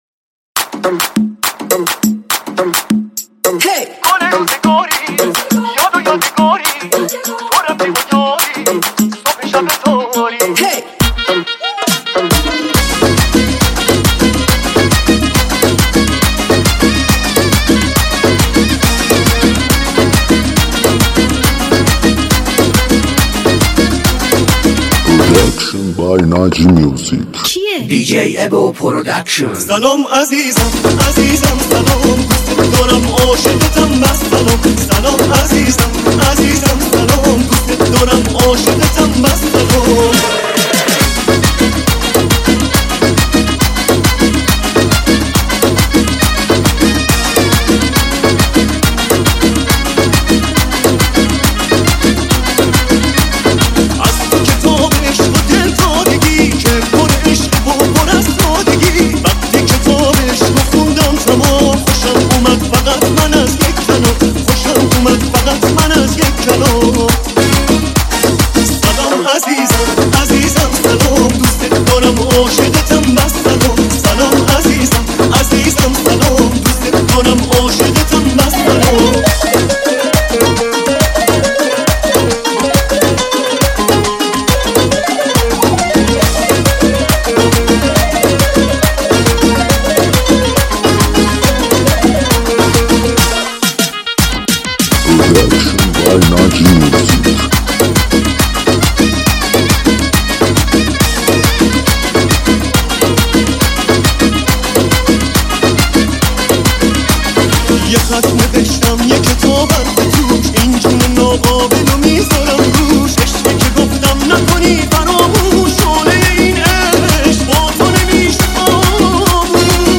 دانلود ریمیکس شاد نوستالژیک